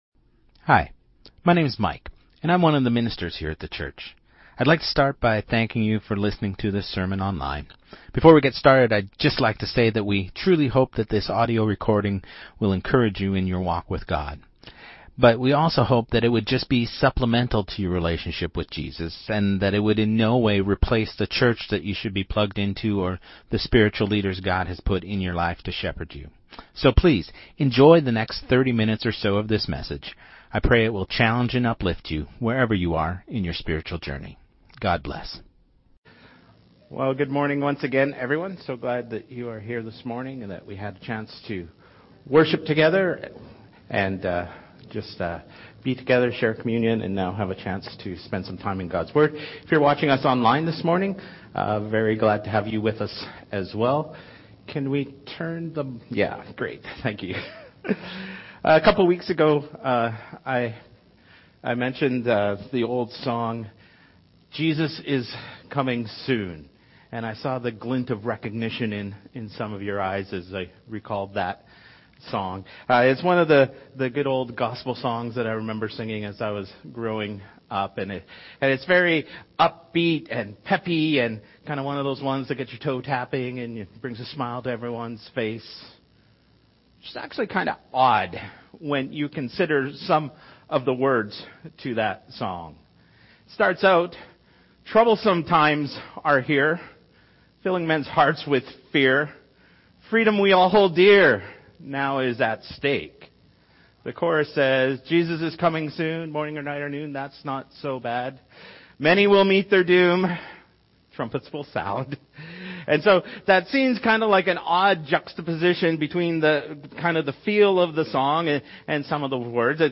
Sermon2026-01-25